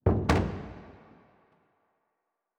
Special Click 26.wav